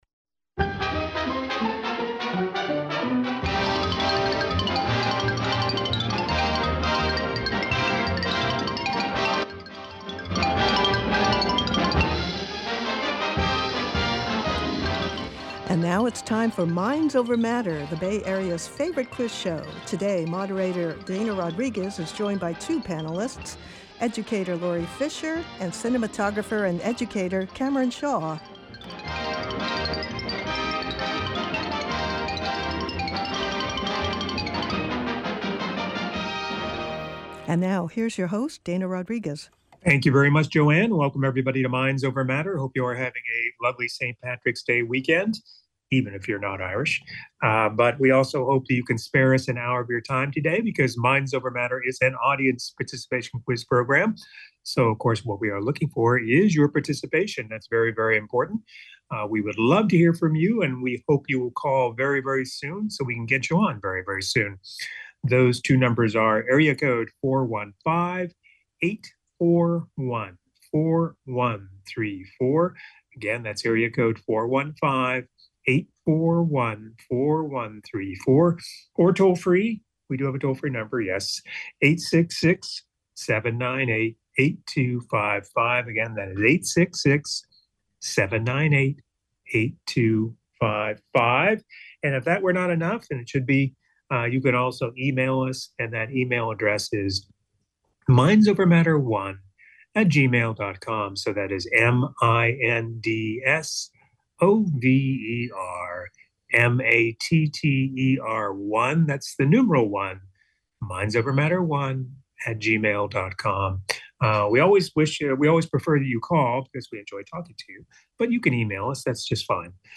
The Bay Area's favorite quiz show!